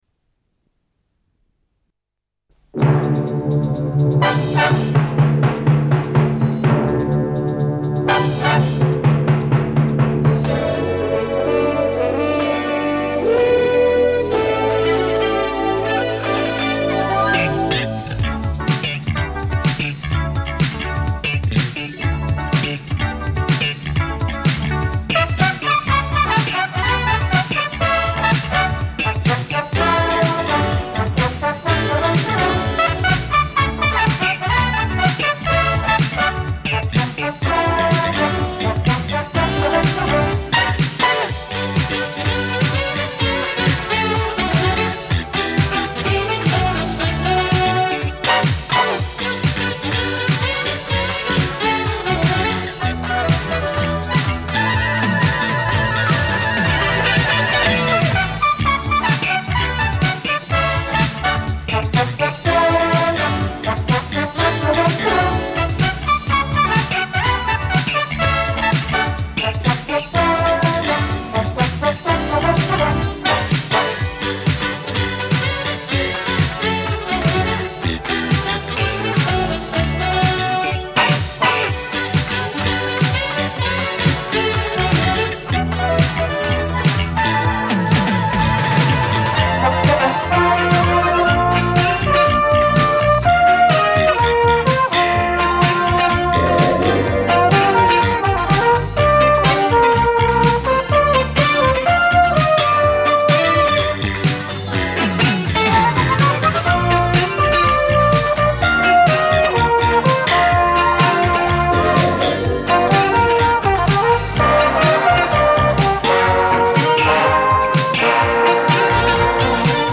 Запись середины 80-х годов.